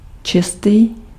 Ääntäminen
IPA: [pyʁ]